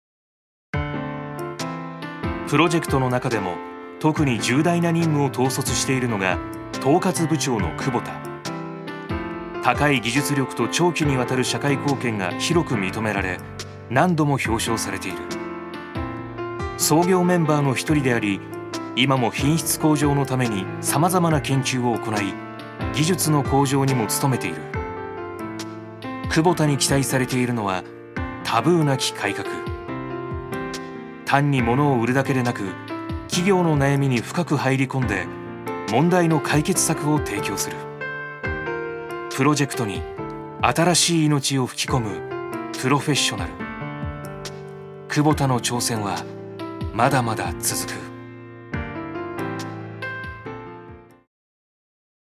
所属：男性タレント
ナレーション７